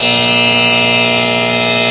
描述：消防警报器有淡入和淡出，频率变化，起始阶段和共振。这首歌是正常化的。就像有人听到火警警笛声。
标签： 喇叭 预警 警报 报警 消防 危险 紧急情况下 警笛 警报器
声道立体声